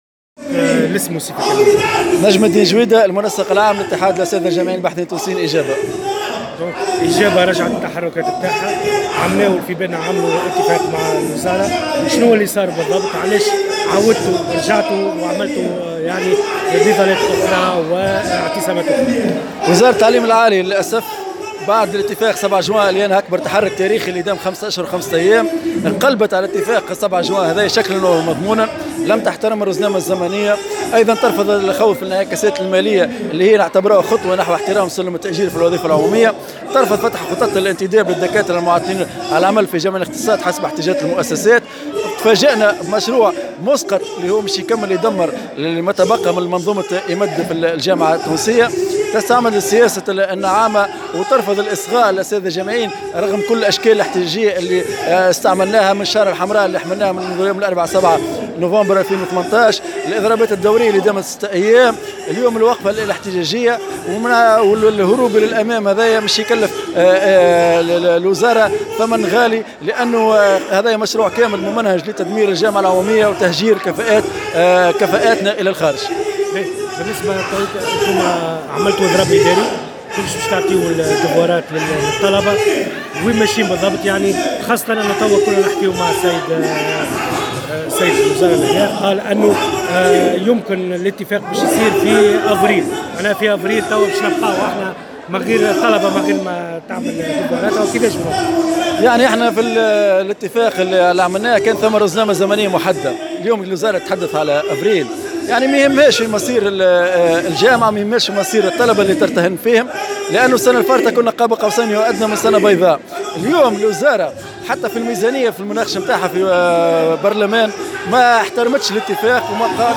نظّم اتحاد الاساتذة الجامعيين الباحثين التونسيين "اجابة"، اليوم الاربعاء، بباحة مقر وزارة التعليم العالي والبحث العلمي، وقفة احتجاجية ندّدوا فيها بالتأخير الحاصل في إصدار القانون الاساسي وعدم استجابة الوزارة لعدد من مطالبهم المهنية.